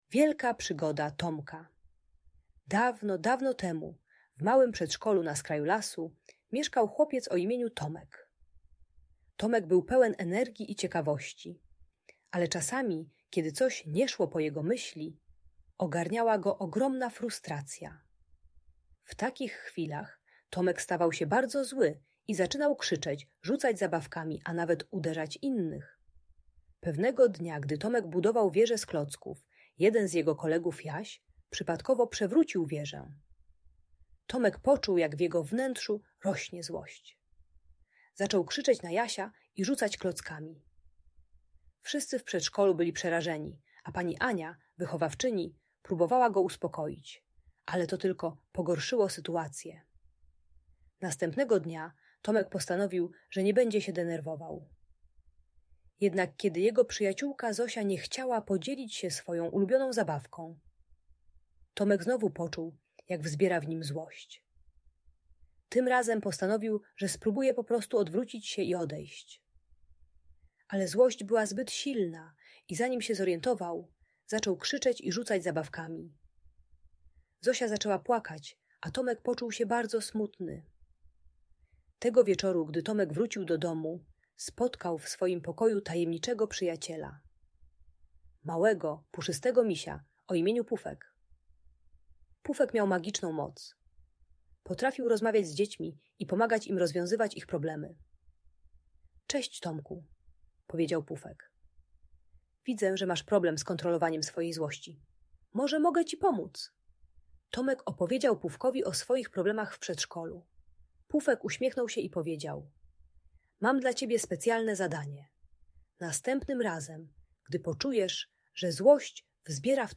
Wielka Przygoda Tomka - Bunt i wybuchy złości | Audiobajka
Historia Tomka uczy techniki głębokiego oddechu i liczenia do dziesięciu, gdy dziecko czuje narastającą frustrację. Audiobajka o złości i agresji dla przedszkolaka - pomaga opanować emocje bez krzywdzenia innych.